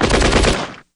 Various MG audio (wav)
machinegun4.wav
machinegun4_160.wav